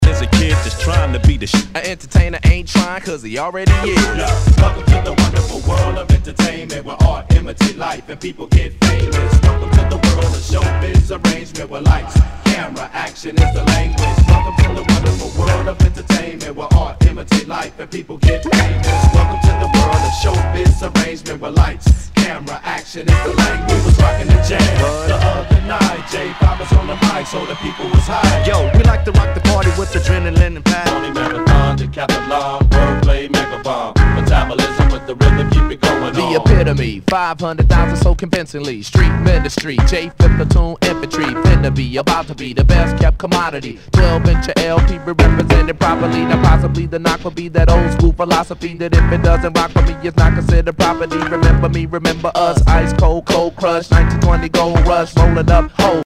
category Rap & Hip-Hop
HIPHOP/R&B